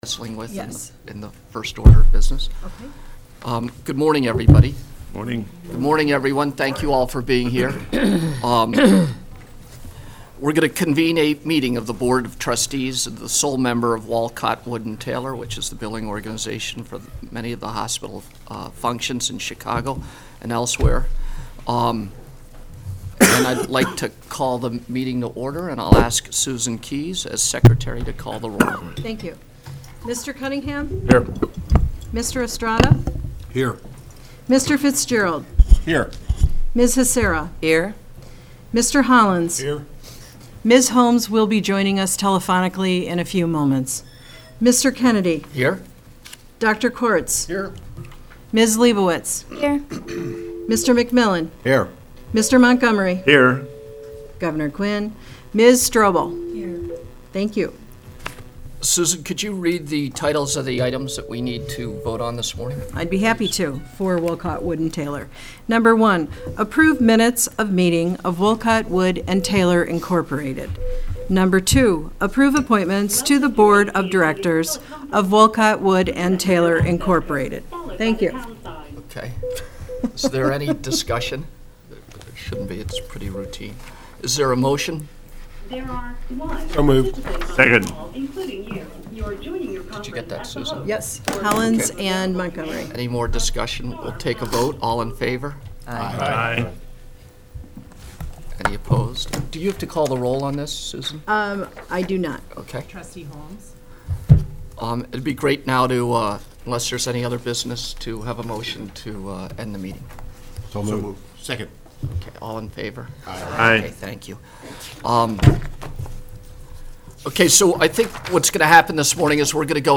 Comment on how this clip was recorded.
Board Meeting Audio Recording: November 14, 2013